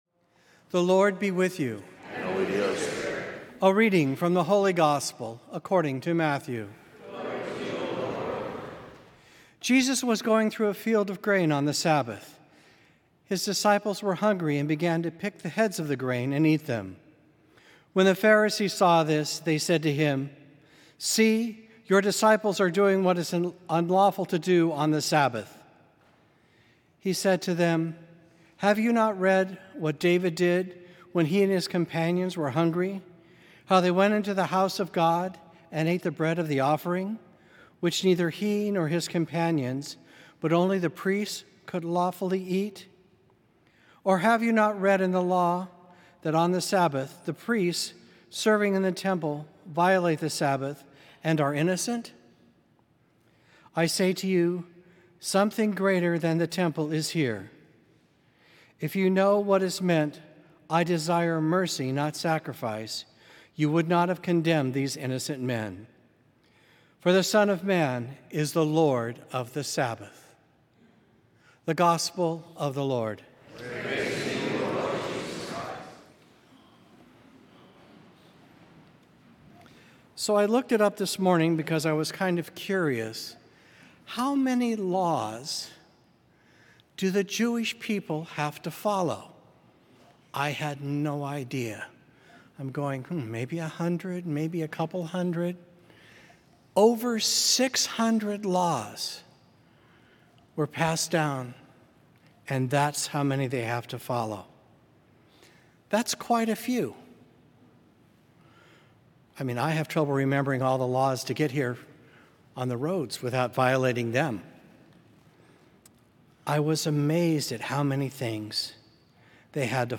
Friday of the Fifteenth Week in Ordinary Time, July 19, 2024